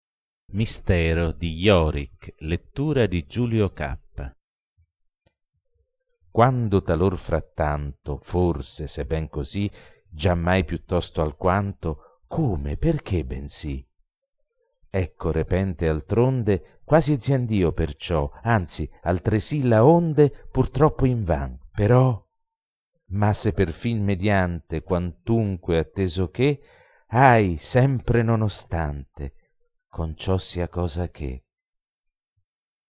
Poesie recitate da artisti